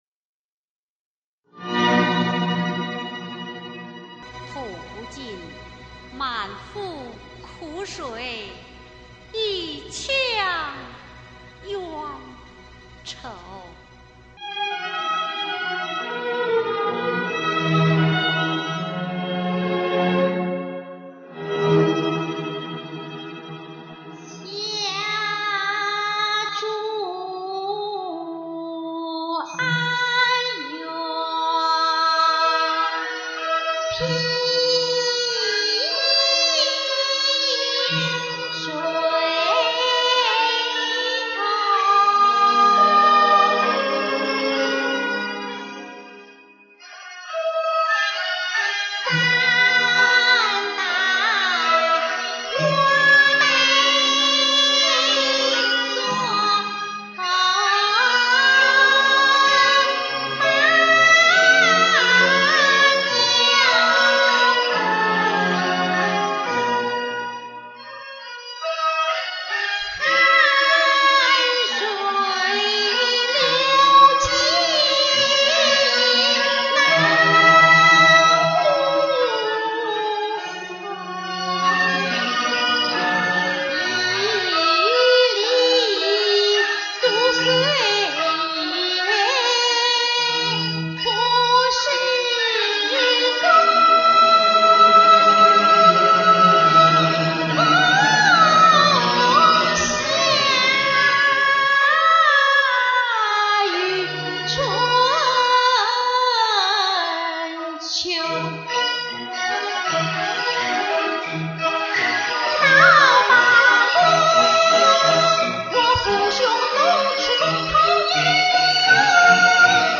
现代京剧